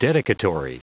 Prononciation du mot dedicatory en anglais (fichier audio)